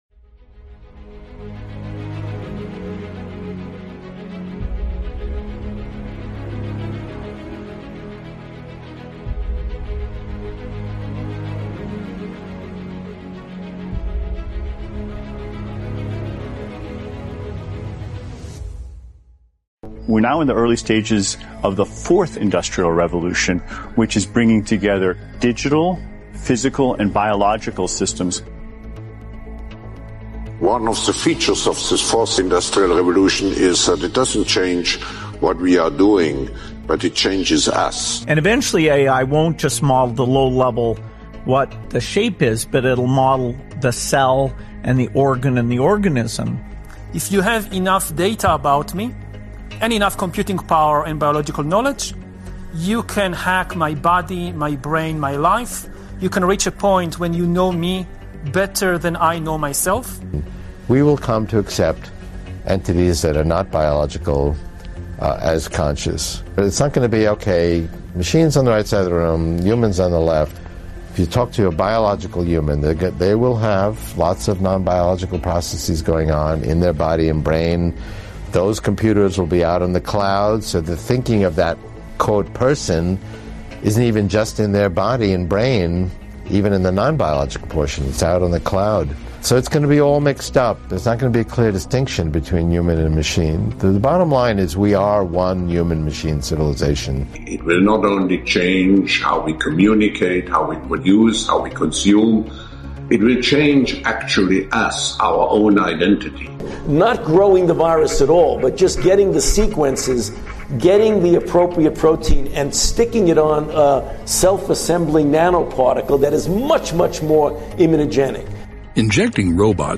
New Atomic Model Includes Consciousness Conversation w